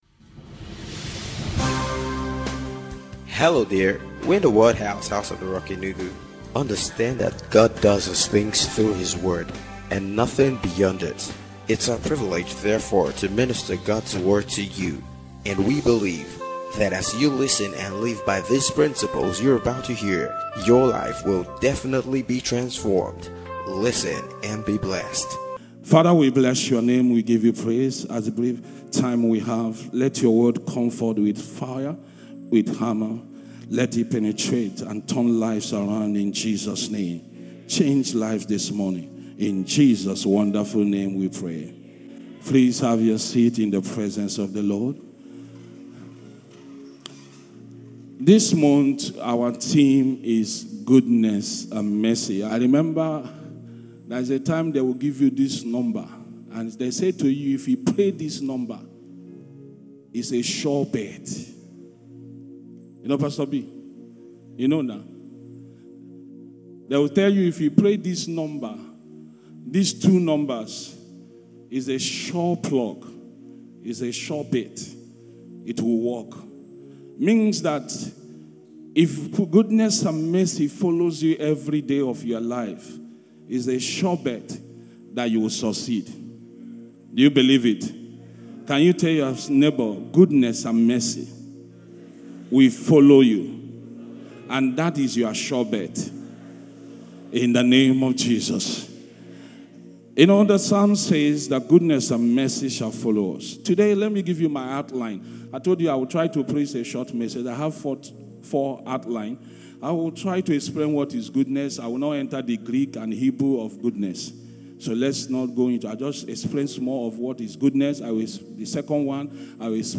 OODNESS AND MERCY - SUNRISE SERVICE